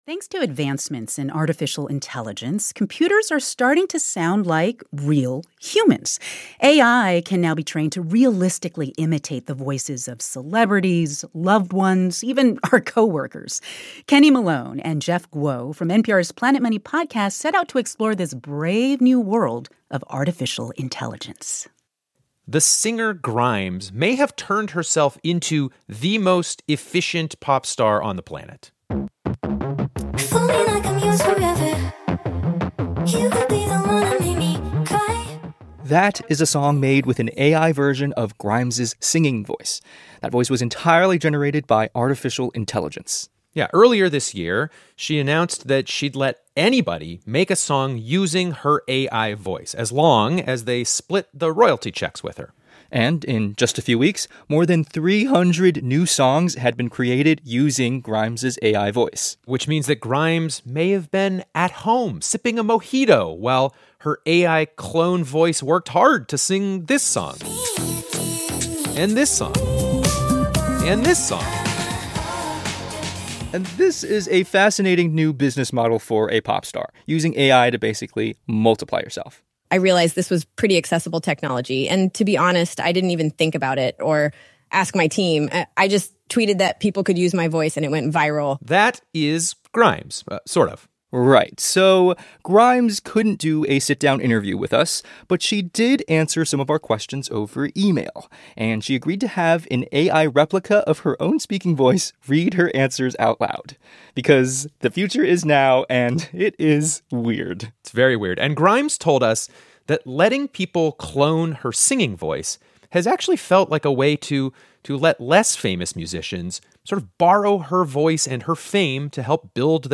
NPR Report on Voice Cloning.